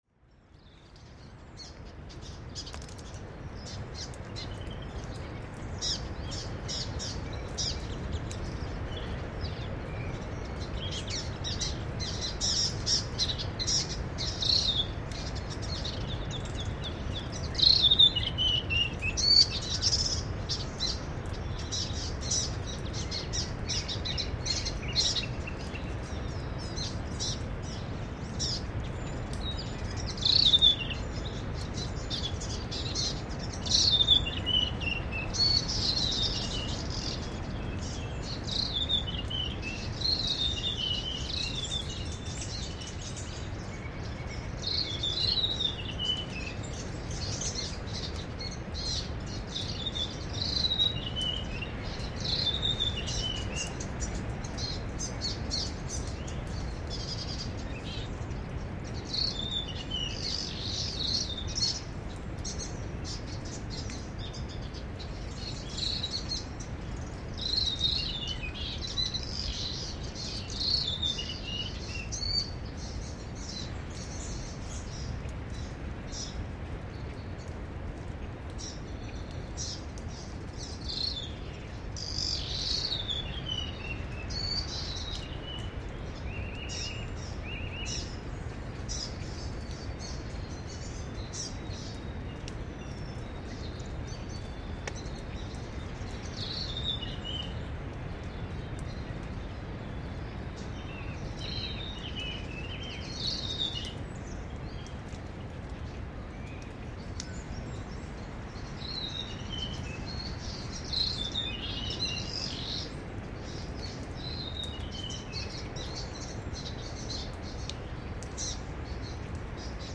Dawn chorus at Okarito
This is a recording of a dawn chorus after two days of hard rain at Ōkārito. Pīpipi Brown Creepers, Mātata Fernbird, Tauhou Silvereyes, and other manu birds are busy feeding and grooming in the warm sunlight. Mixing with this birdsong is the constant rumble of waves that can be heard wherever you are in town. The trail where this was recorded was dripping with rainwater and steaming as the temperature quickly rose.